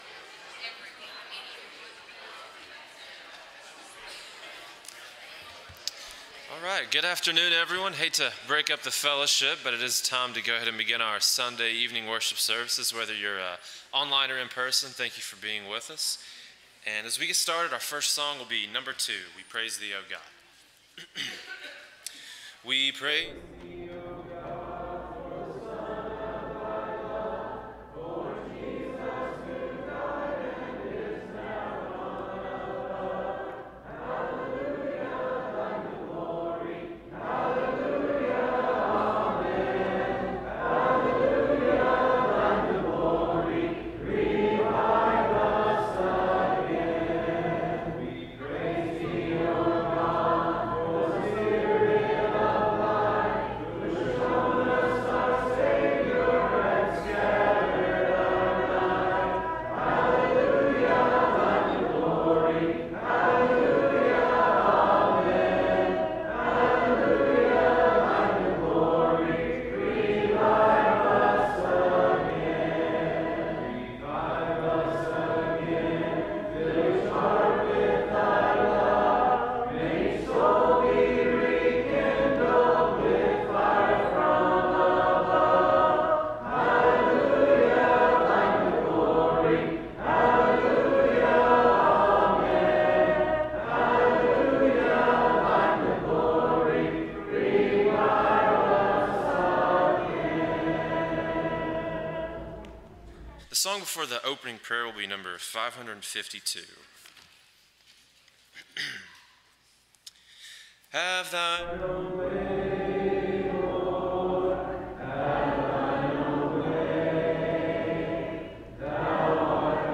John 10:10, English Standard Version Series: Sunday PM Service